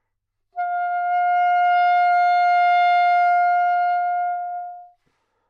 萨克斯中音单音（弹得不好） " 萨克斯中音F5音高不好，动态不好，音色不好
描述：在巴塞罗那Universitat Pompeu Fabra音乐技术集团的goodsounds.org项目的背景下录制。单音乐器声音的Goodsound数据集。 instrument :: sax_altonote :: Foctave :: 5midi note :: 65microphone :: neumann U87tuning reference :: 442.0goodsoundsid :: 4928 故意扮演一个坏音调坏动态坏音色的例子
标签： 好声音 单注 多样本 萨克斯 纽曼-U87 F5 奥拓
声道立体声